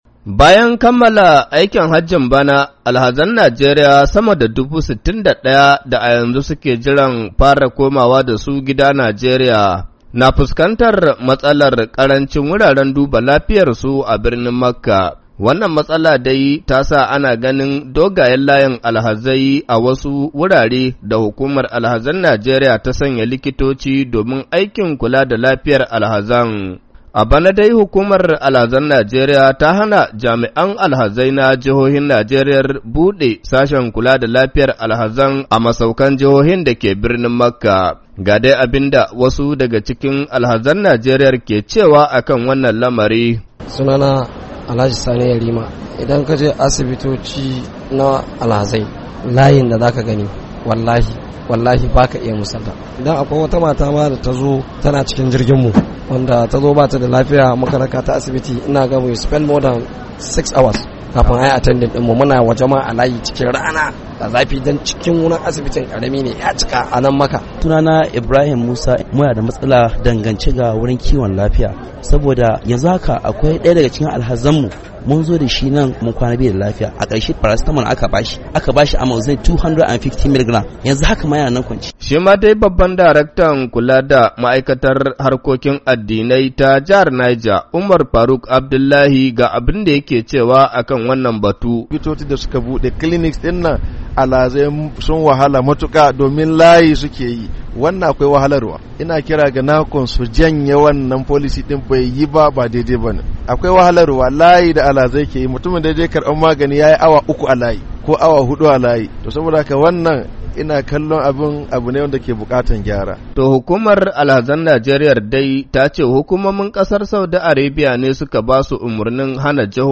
daga Makka.